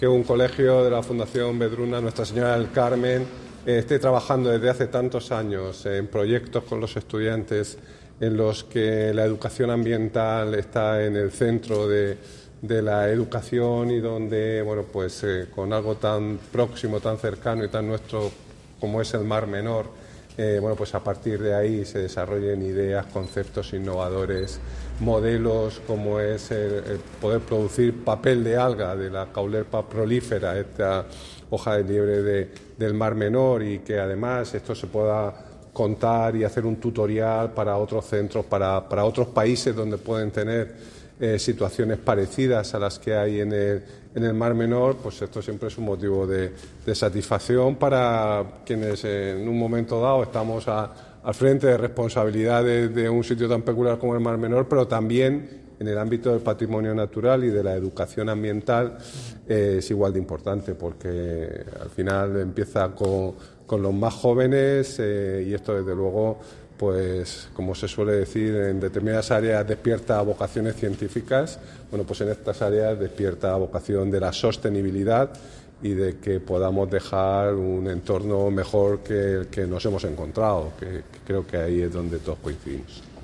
• Audio del Consejero de Medio Ambiente, Universidades, Investigación y Mar Menor, Juan María Vázquez